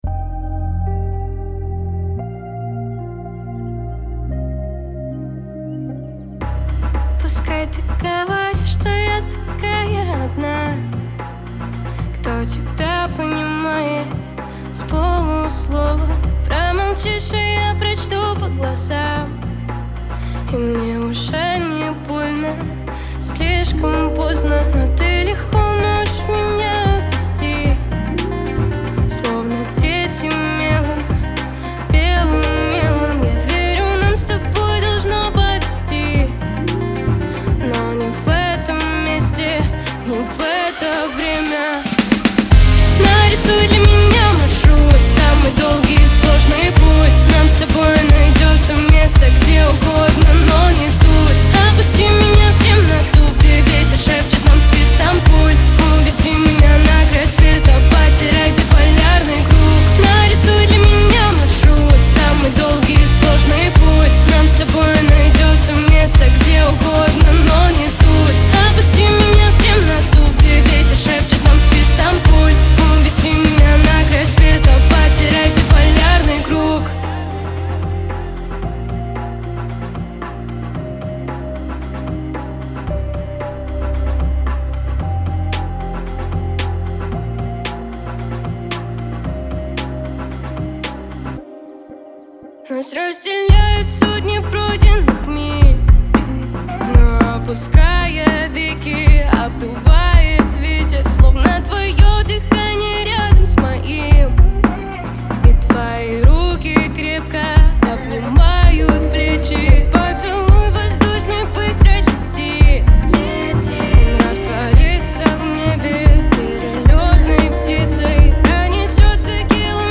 инди-поп, наполненная меланхолией и мечтательностью.